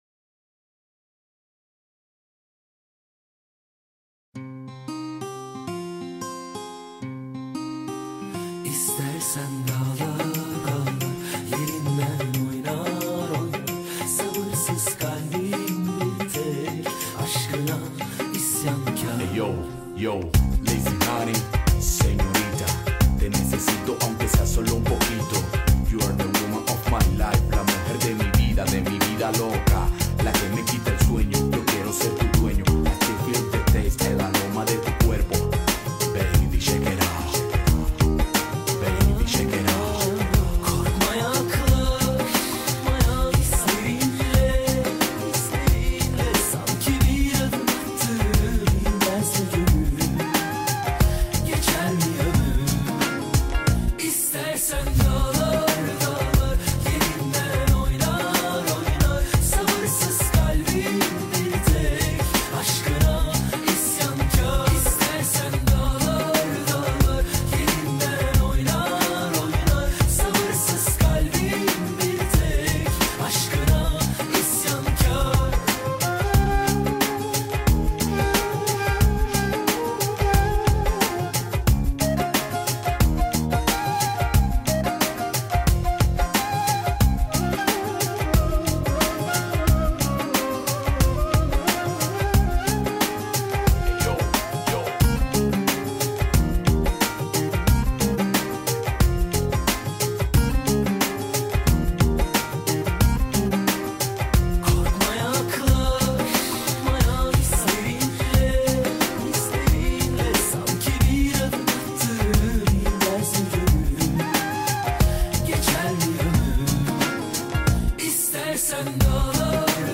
با صدای مرد ترکی